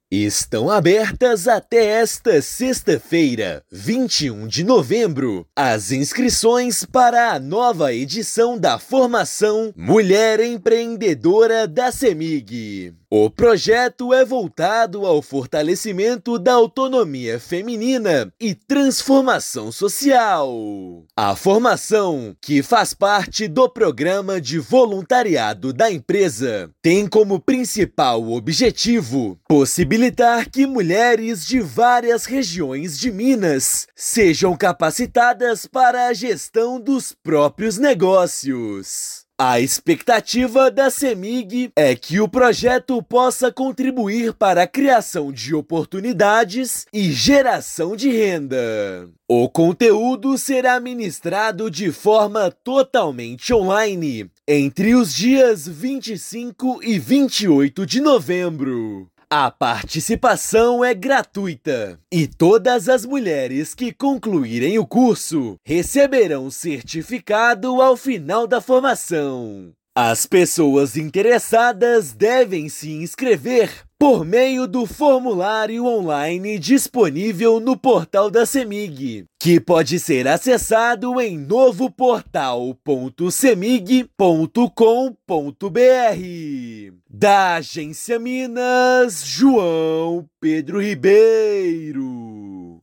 Ação Mulher Empreendedora On-line impulsiona geração de renda e contribui para transformação social. Ouça matéria de rádio.